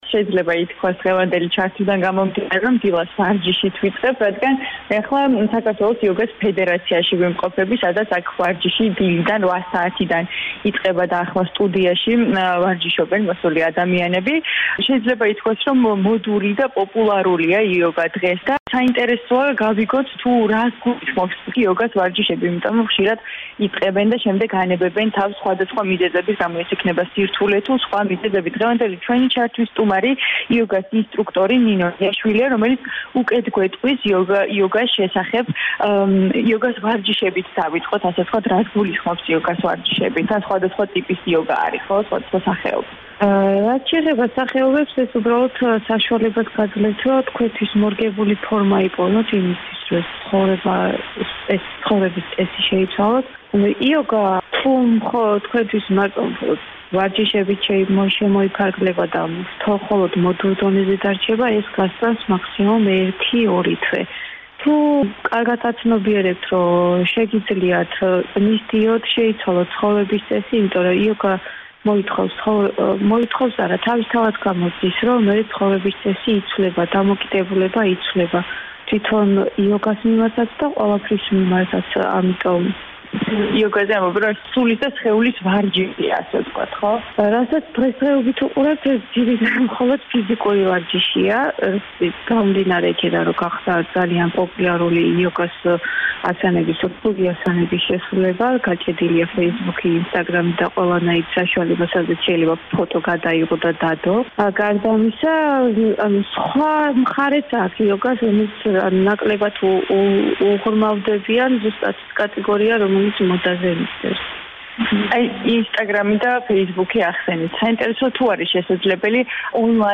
რადიო თავისუფლება რადიო თავისუფლება